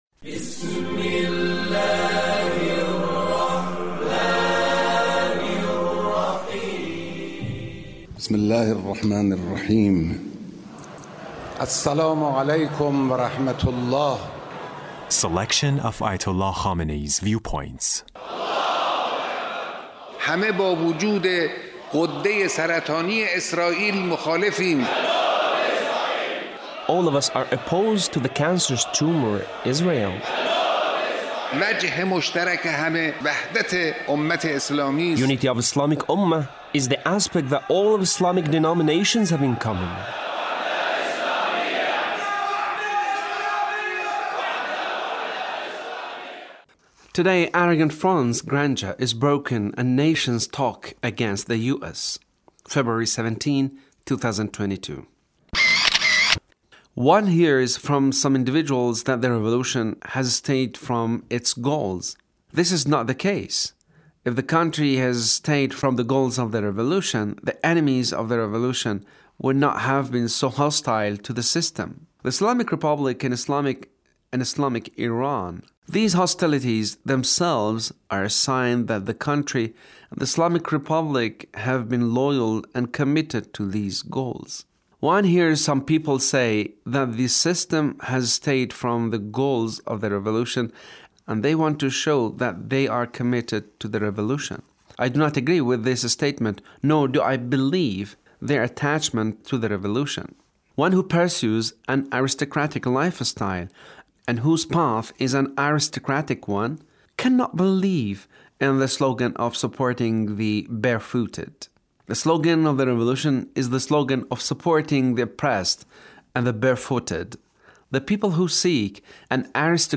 Leader's speech (1426)